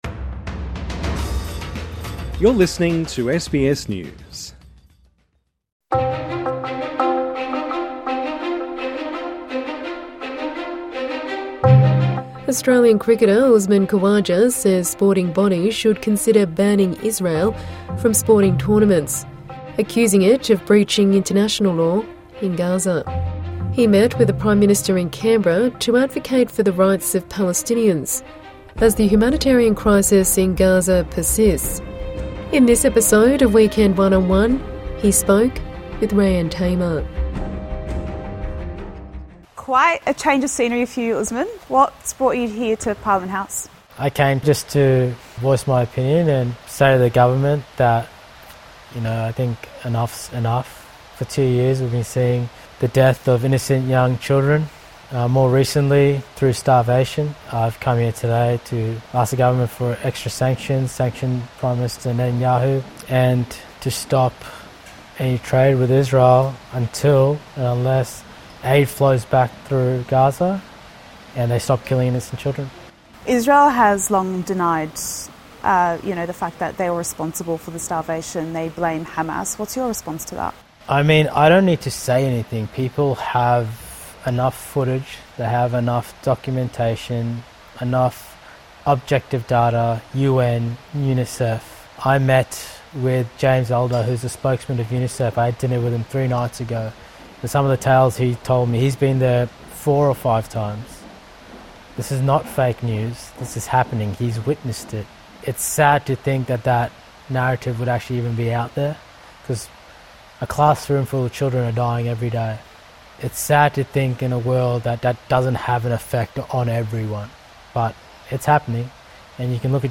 INTERVIEW: Cricketer Usman Khawaja on why he is calling on sporting bodies to consider banning Israel